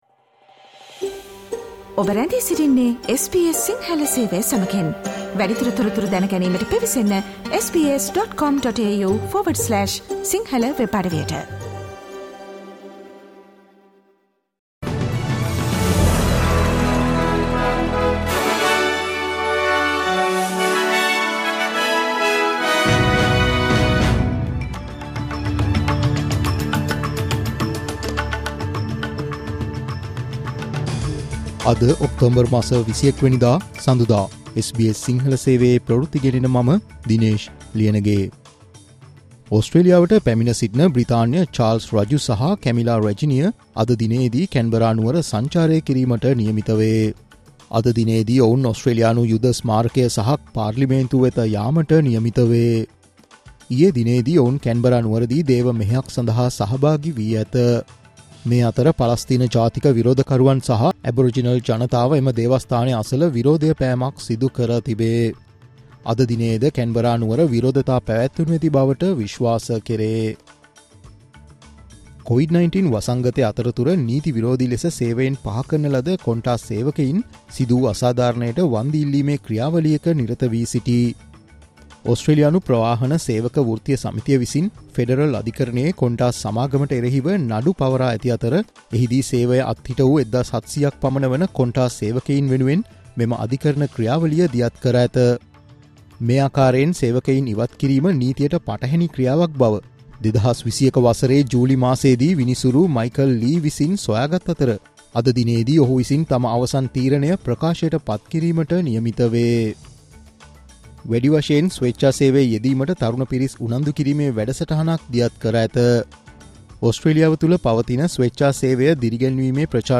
Australia's news in Sinhala.